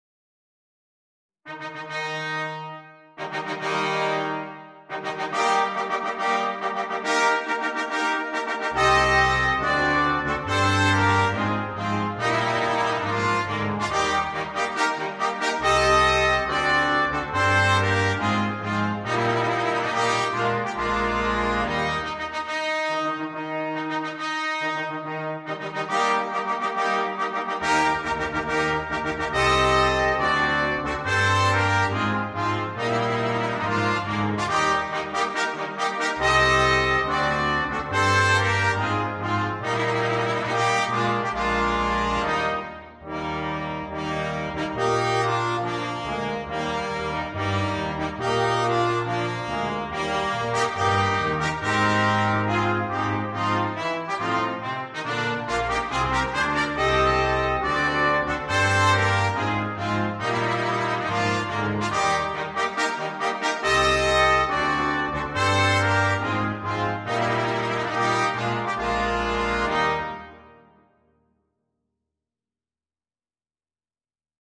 Ноты cвадебного марша для брасс-квинтета.